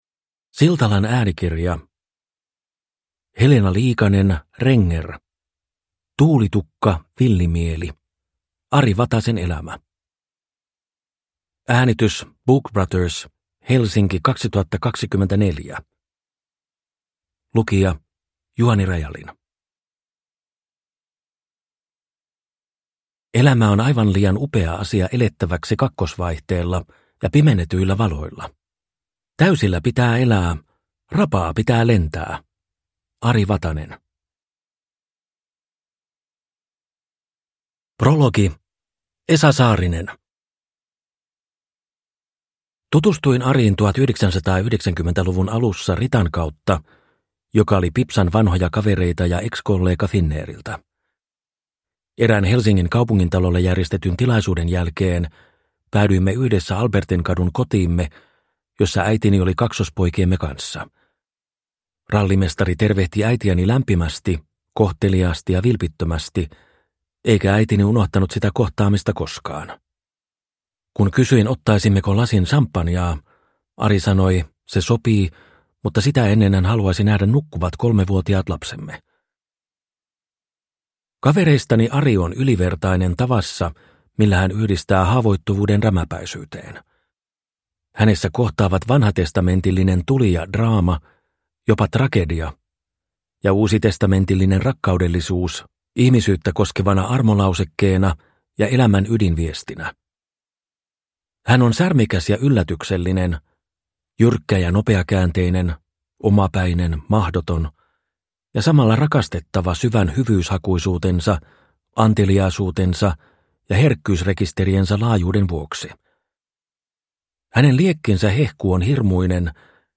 Tuulitukka, villimieli (ljudbok) av Helena Liikanen-Renger